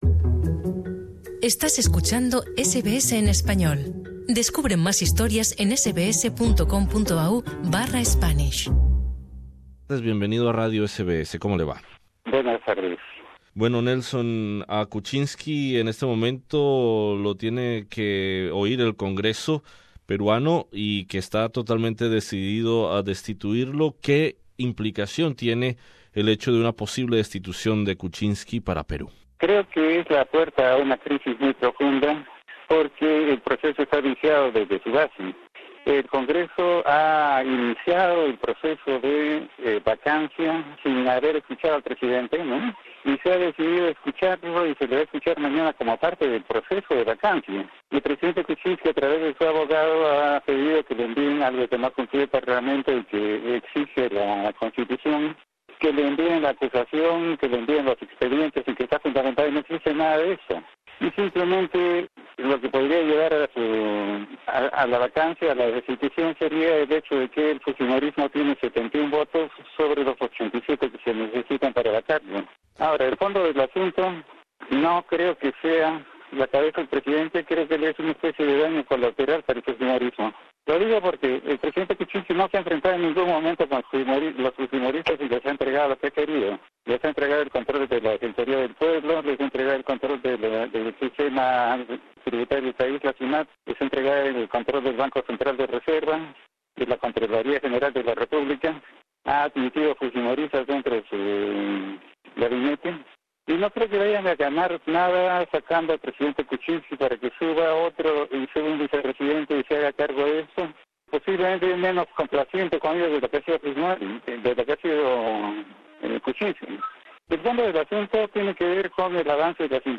Entrevista con el politólogo de la Universidad Católica de Peru